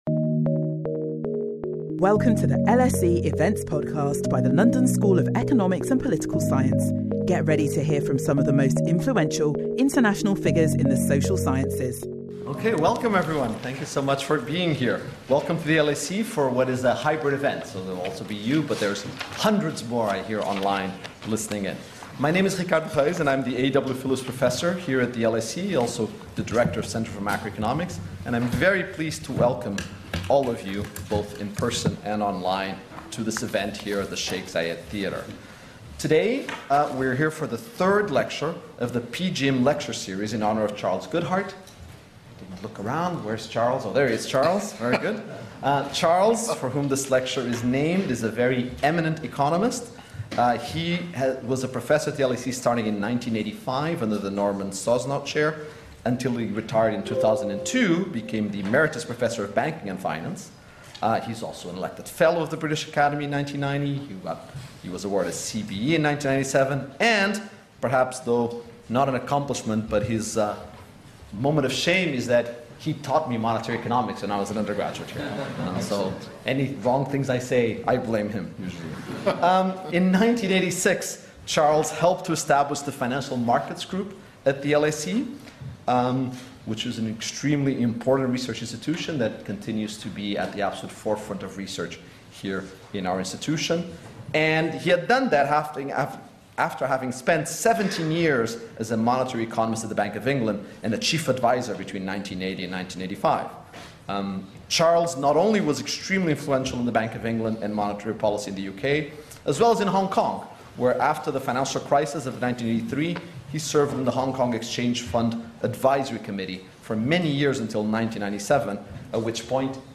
Governor of the Bank of England Andrew Bailey delivers his lecture on the key role that central bank reserves play in financial stability and monetary policy in honour of LSE's Charles Goodhart.